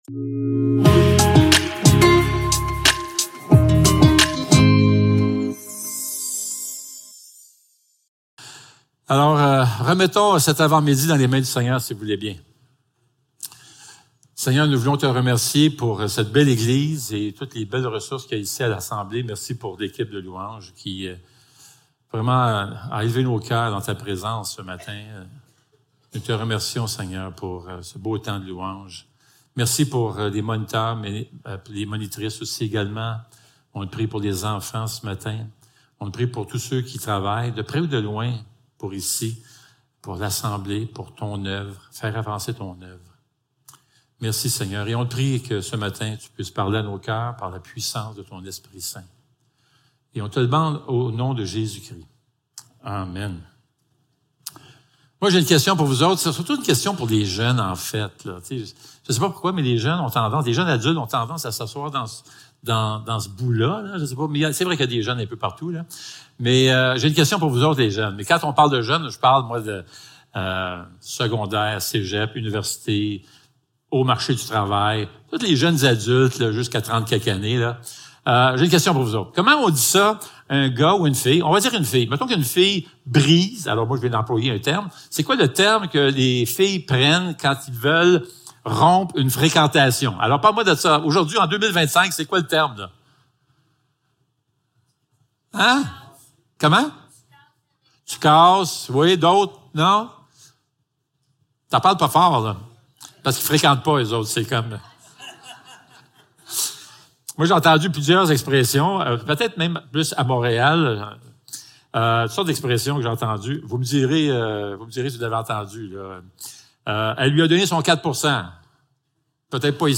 1 Jean 4.7-21 Service Type: Célébration dimanche matin Description